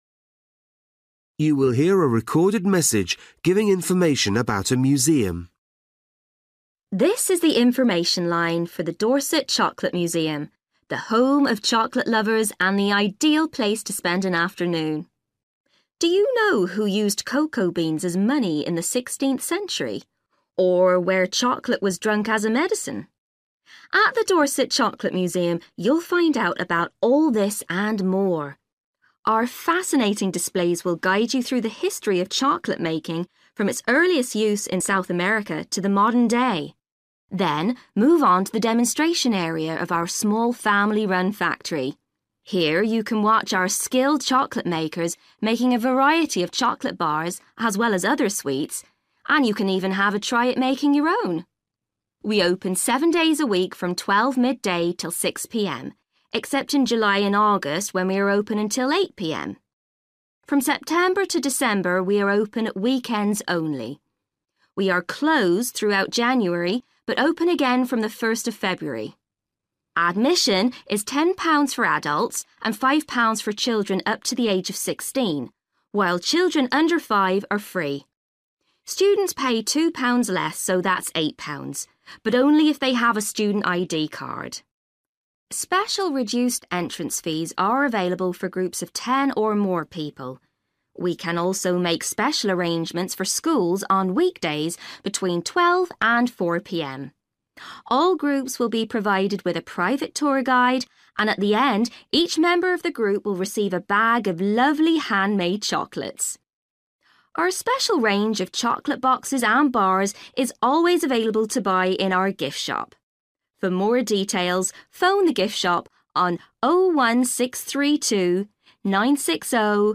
You will hear a recorded message giving information about a museum.